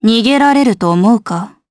Valance-Vox_Skill5_jp.wav